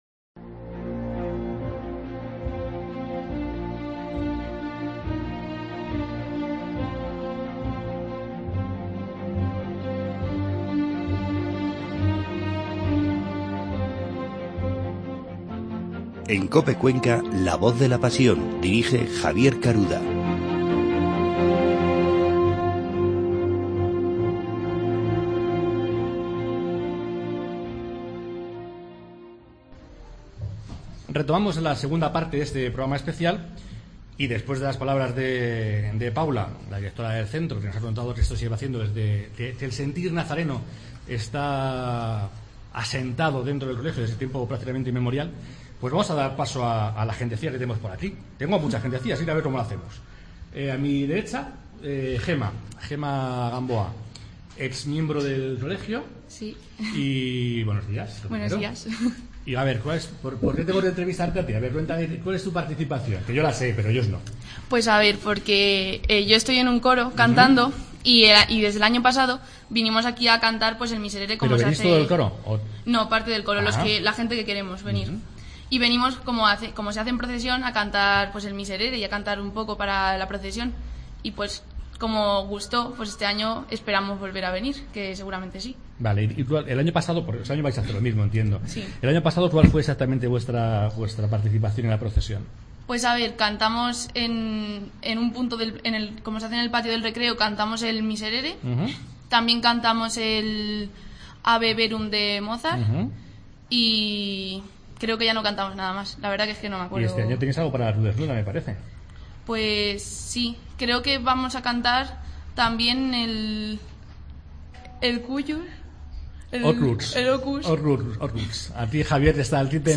Segunda parte del programa realizado en el colegio Fray Luis de León.